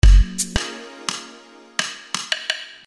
lemonade neptune percussion loop.mp3